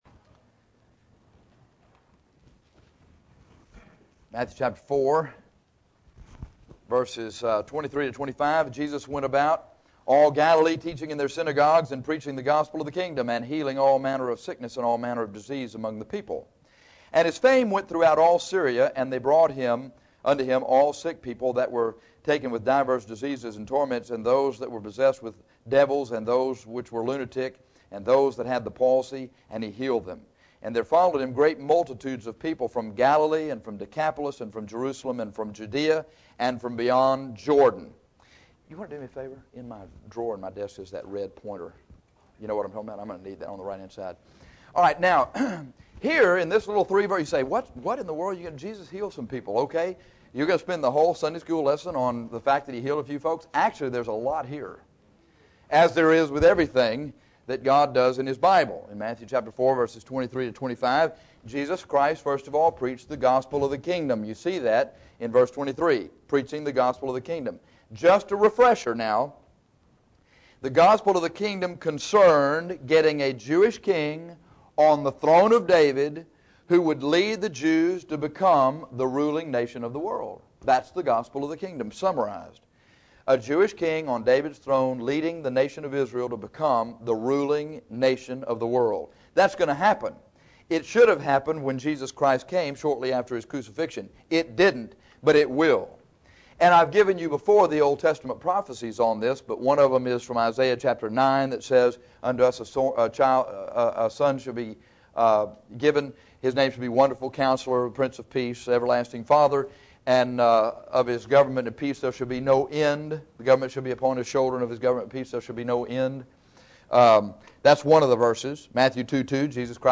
This Sunday school lesson reveals the prophecy concerning and necessity of the healing ministry of Jesus.